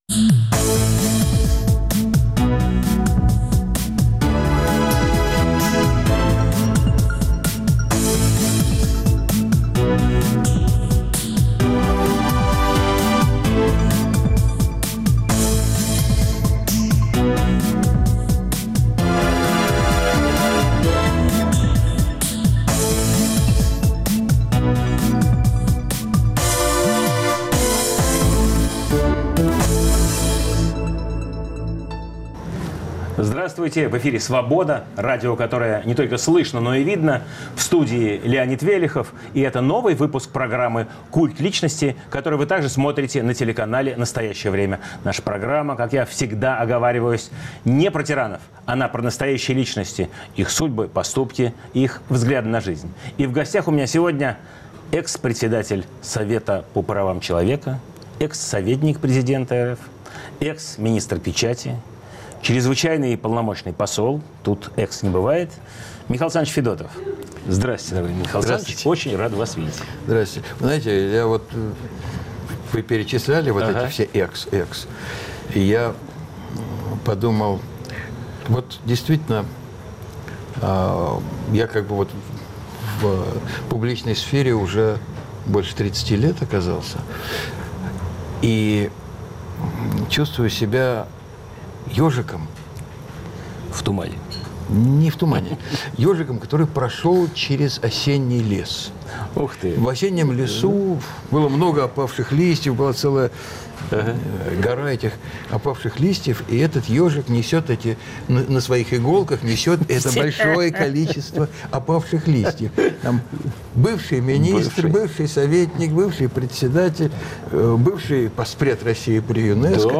В студии нового выпуска программы – бывший председатель Совета по правам человека при президенте РФ.